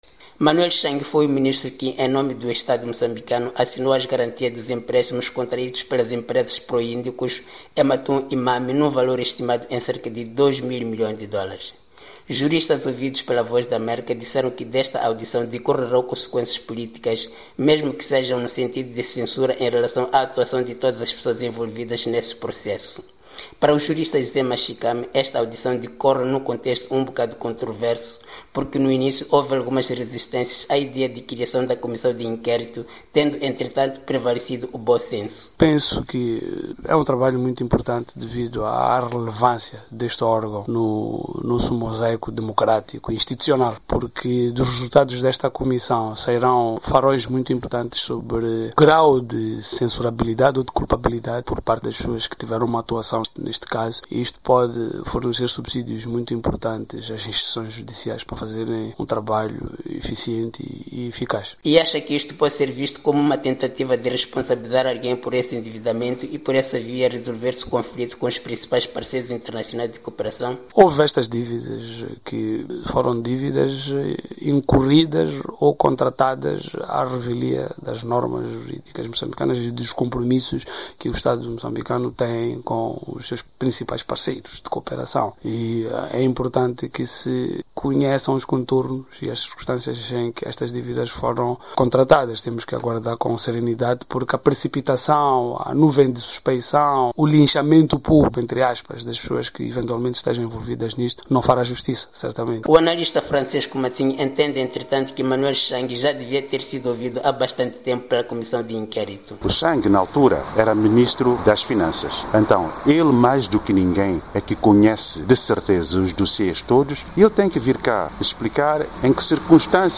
Juristas ouvidos pela VOA disseram que desta audição decorrerão consequências políticas, mesmo que sejam no sentido de censura em relação à actuação de todas as pessoas envolvidas nestes escândalos.